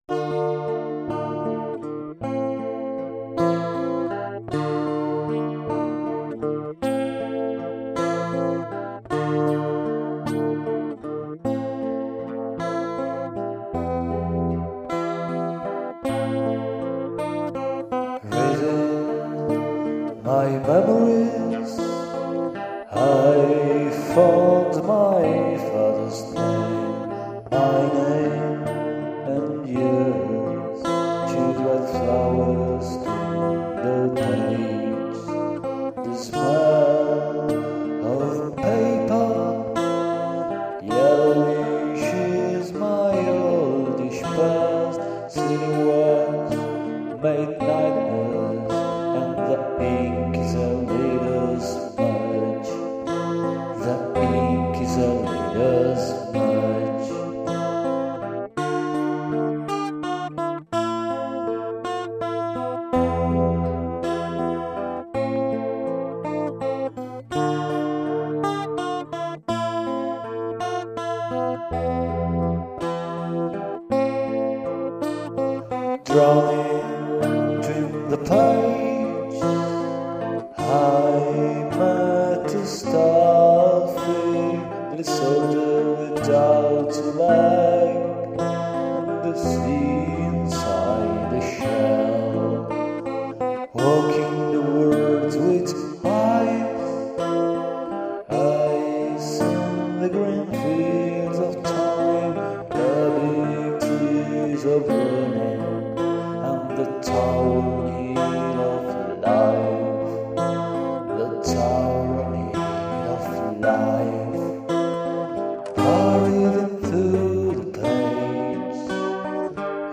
compatta iterazione tra le due chitarre
tastiera
violoncello